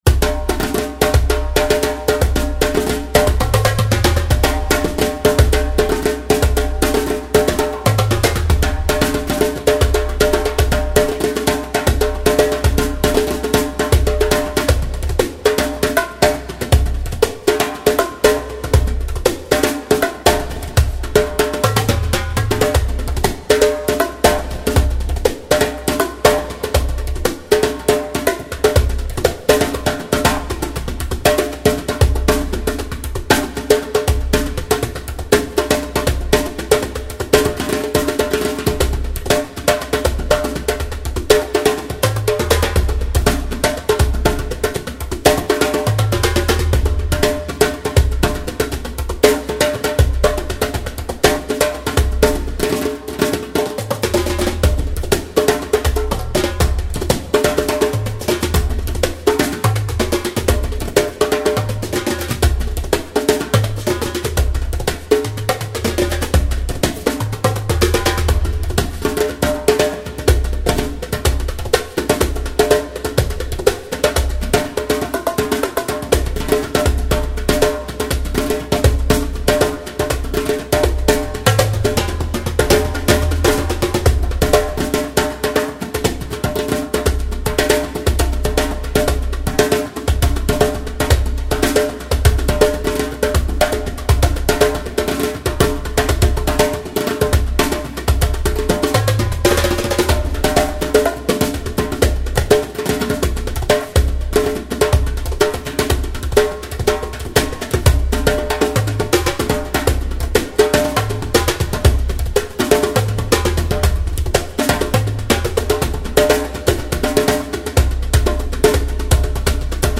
African Percussion Audio Sample
(Recorded with no overdubs on 5 Djembes and 2 Bongos)
Percussion Solo.mp3